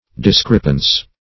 Discrepance \Dis*crep"ance\ (?; 277), Discrepancy